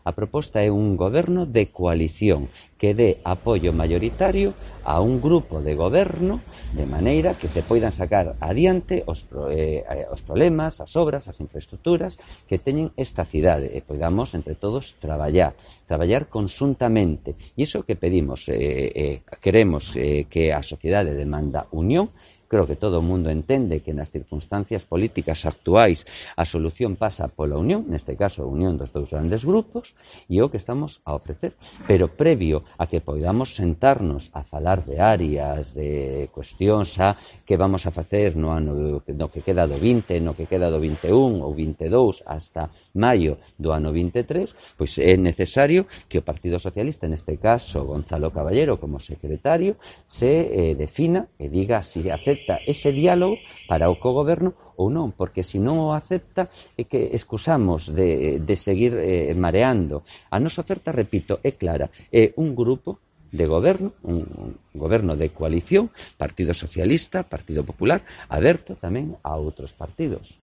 Declaraciones de Jesús Vázquez, presidente del PP de Ourense